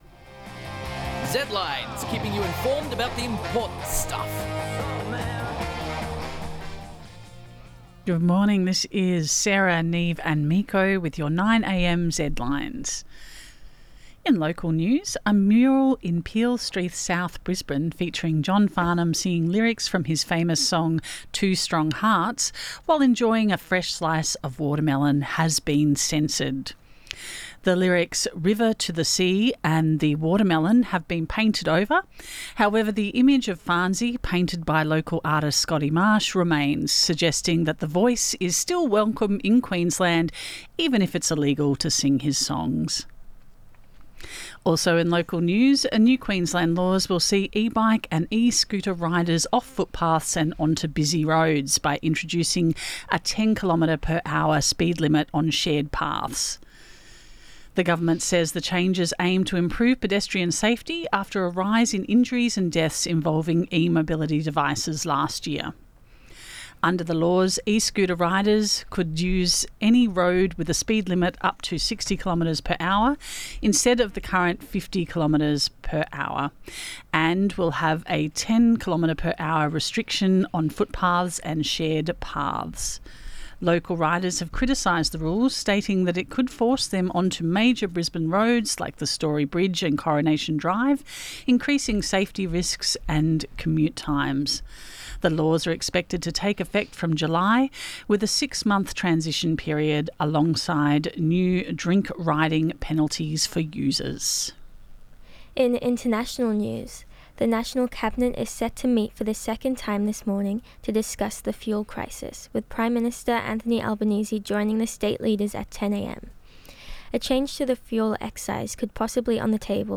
Zedlines Bulletin